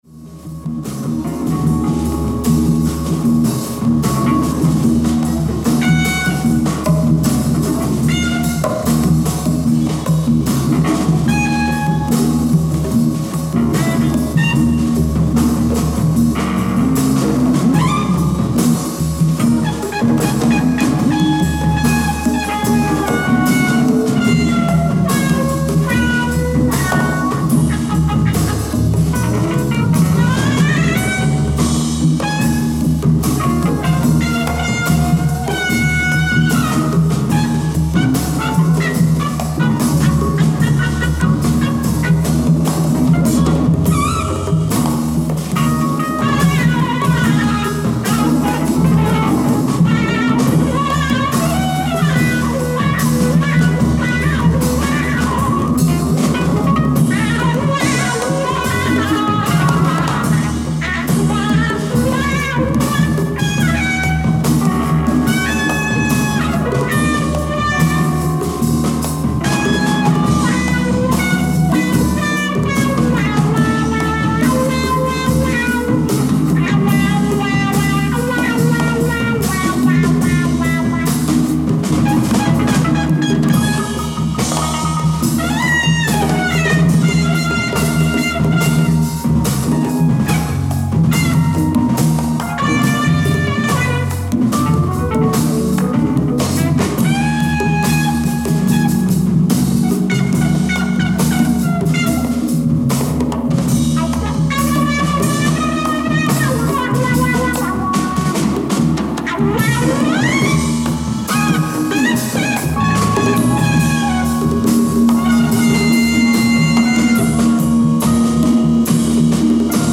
ライブ・アット・ベオグラード、セルビア 11/03/1971
※試聴用に実際より音質を落としています。